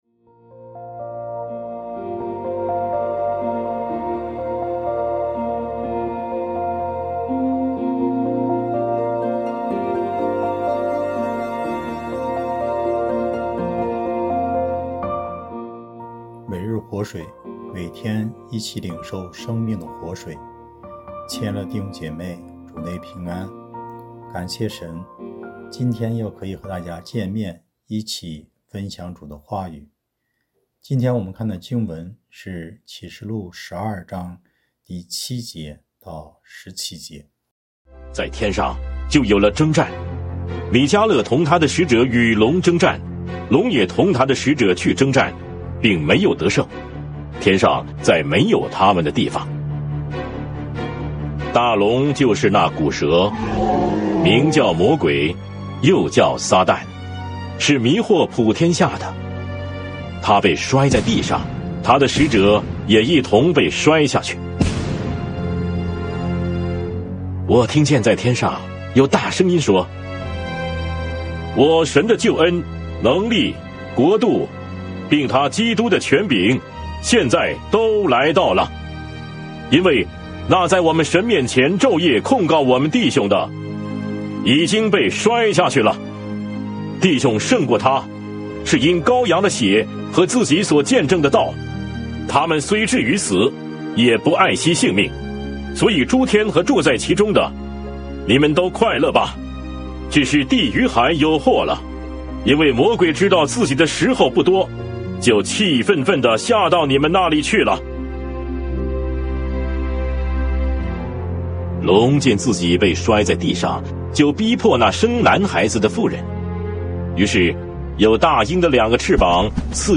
牧/者分享